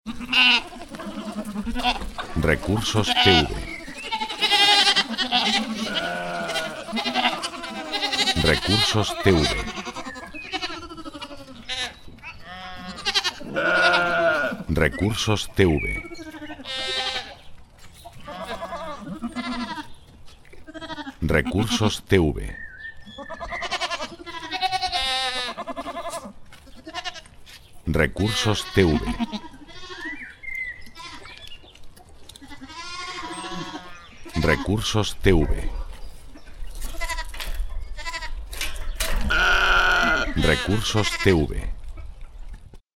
efecto de balido de ovejas en el campo
balido_oveja.mp3